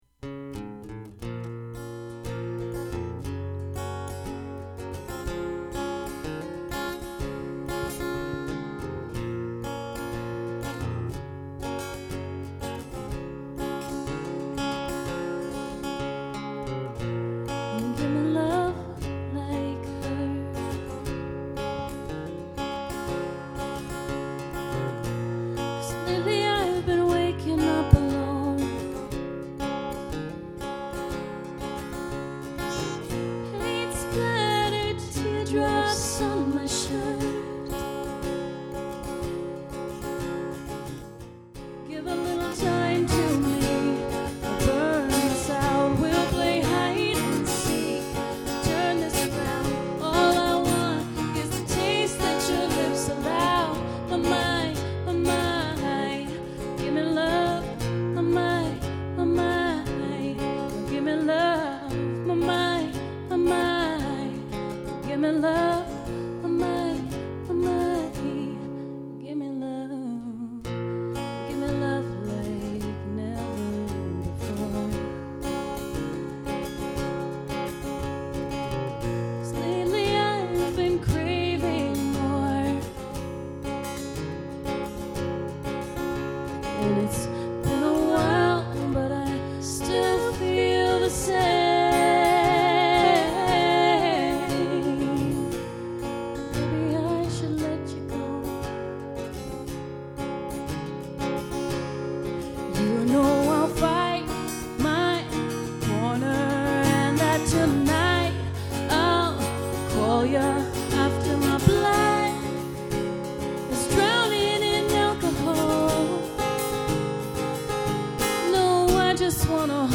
(Cover Preview)
Vocals
Guitar